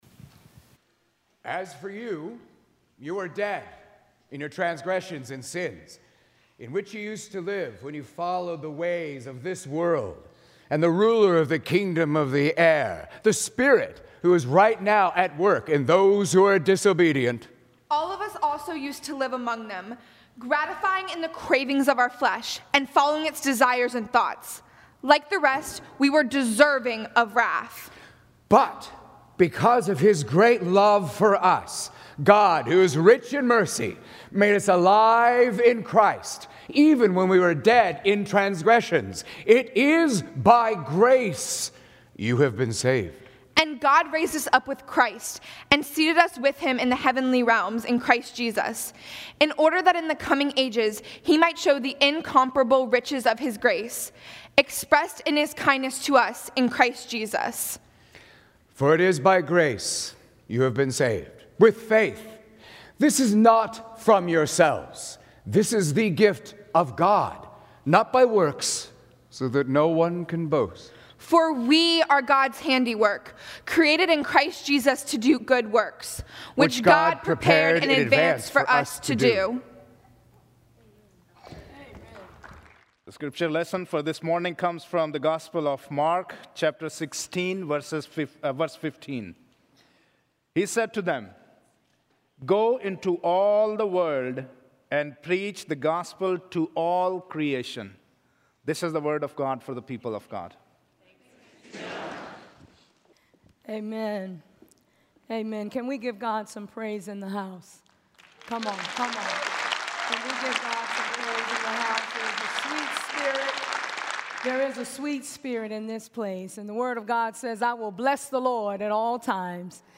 Speaker: Bishop Sharma Lewis Scripture: Ephesians 2:1-10 & Mark 16:15, Bishop Sharma D. Lewis is the resident bishop of the Richmond episcopal area.